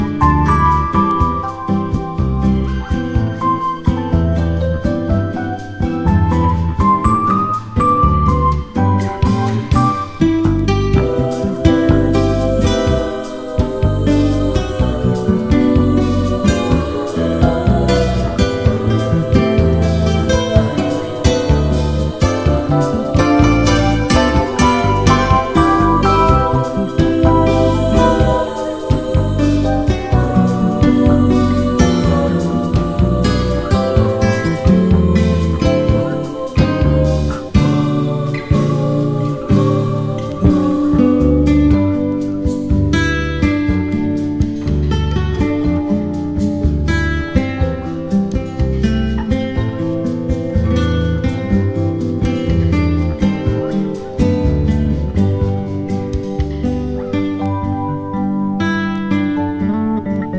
JAPANESE FUSION / BRAZILIAN FUSION
和モノ・ブラジリアン・フュージョン傑作！
アーバン感漂う軽快なシティ・サンバ